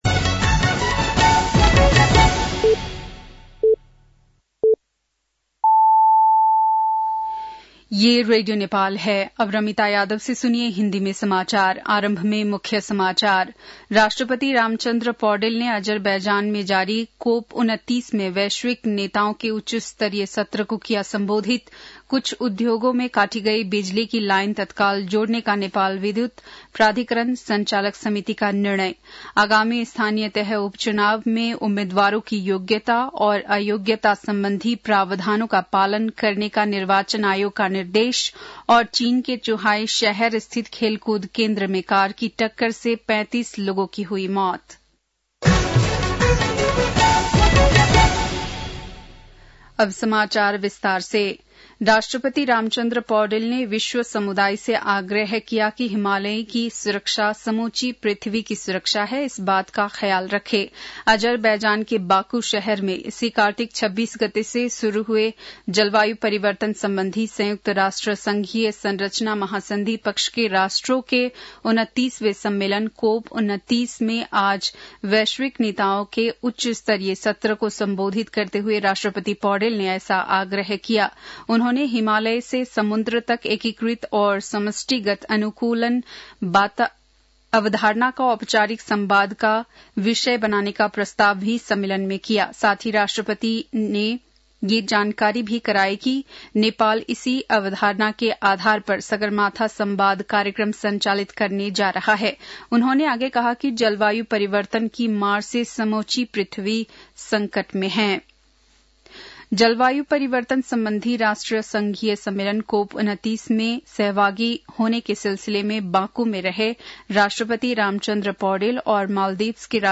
बेलुकी १० बजेको हिन्दी समाचार : २८ कार्तिक , २०८१